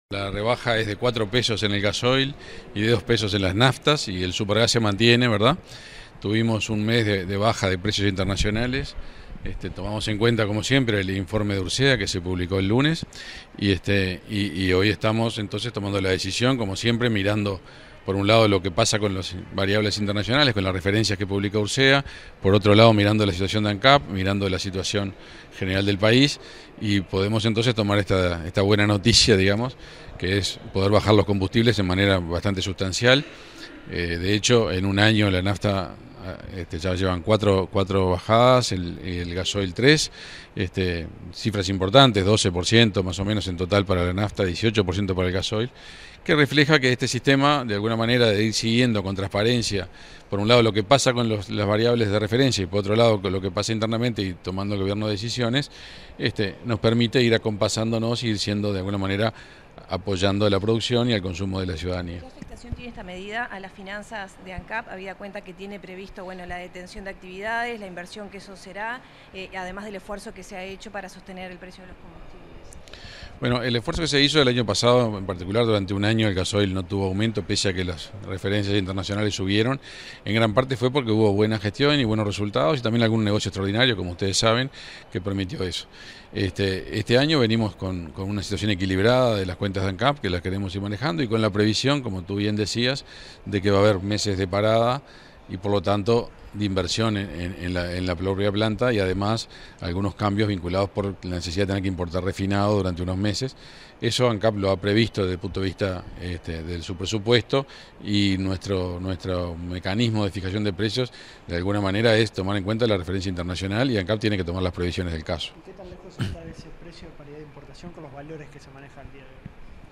Declaraciones del ministro de Industria, Energía y Minería, Omar Paganini